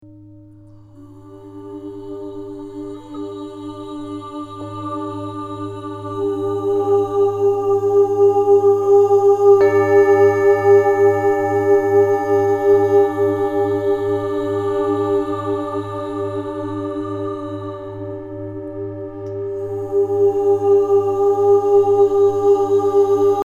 resonance-extrait-1-voix-et-bols.mp3